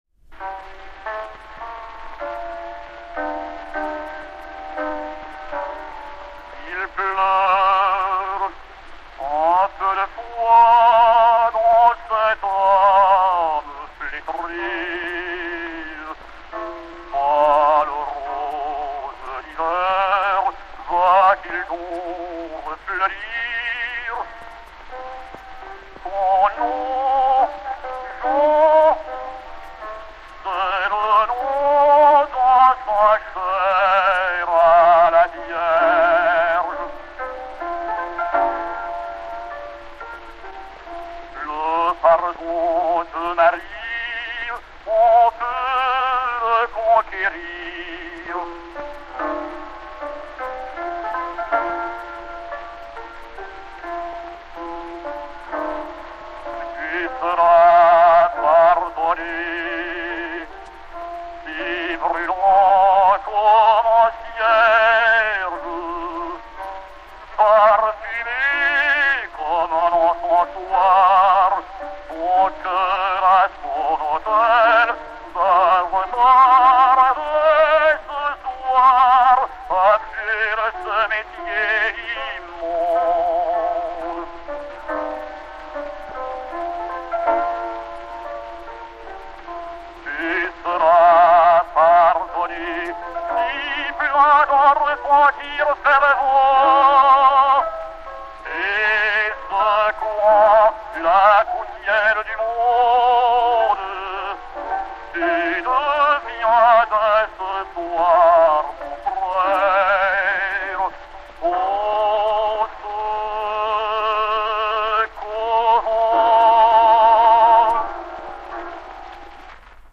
et Piano